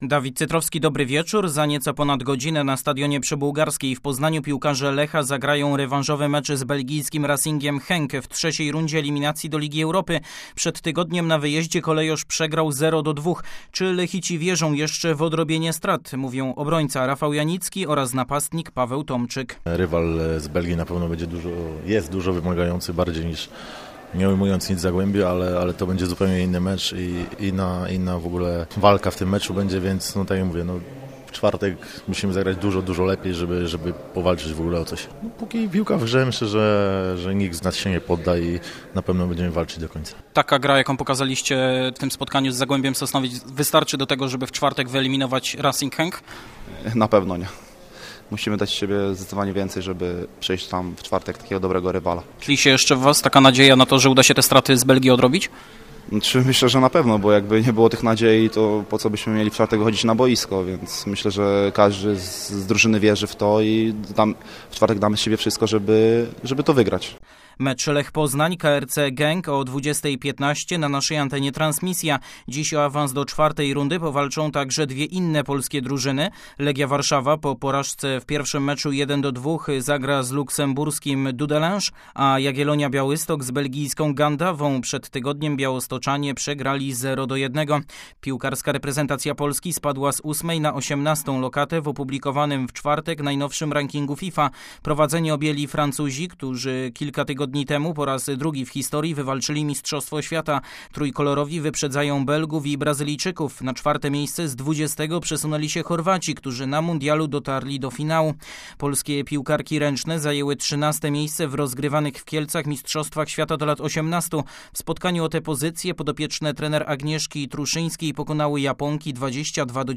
16.08 serwis sportowy godz. 19:05